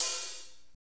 HAT F S L0CL.wav